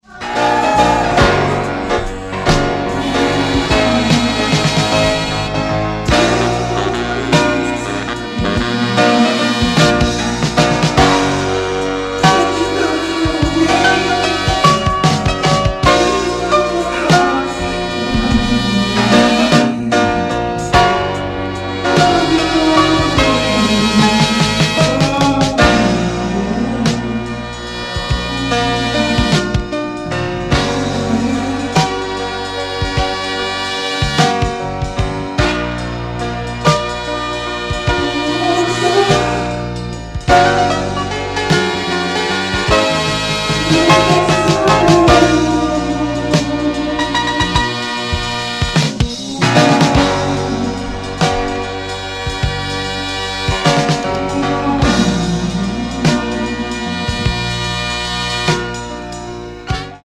Early new jack swing